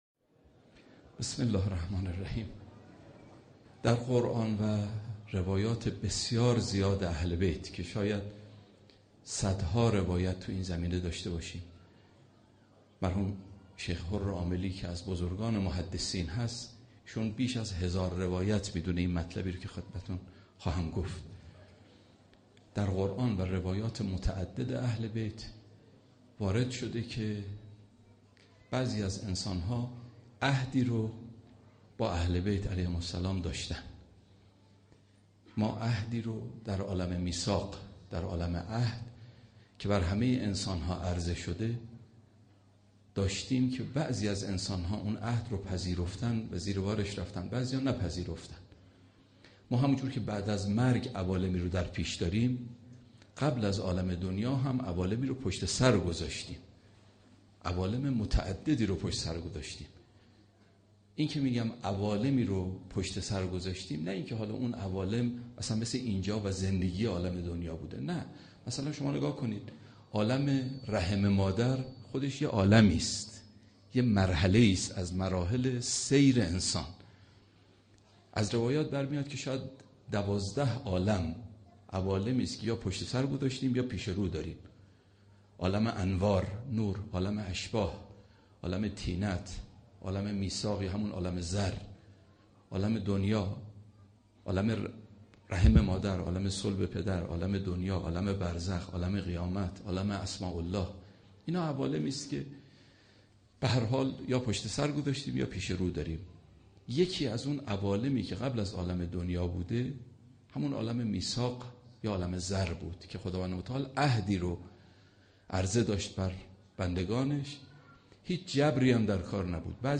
شب هشتم محرم 95_سخنرانی